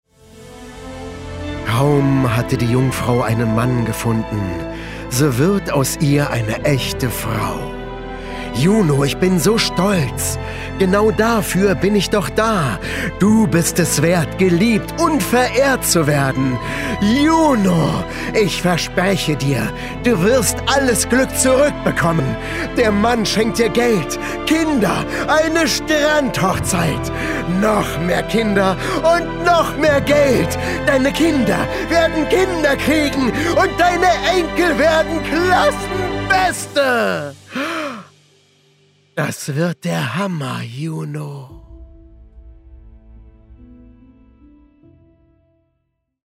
markant, sehr variabel
Mittel plus (35-65)
Audio Drama (Hörspiel), Scene